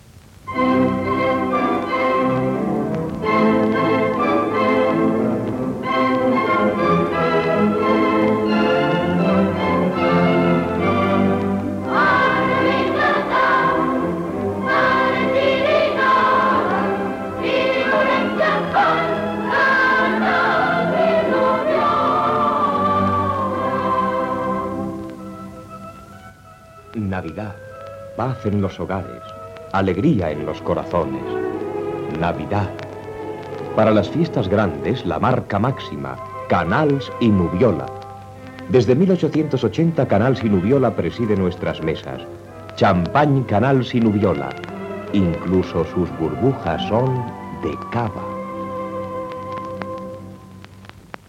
Descripció Publicitat Canals Nubiola Gènere radiofònic Publicitat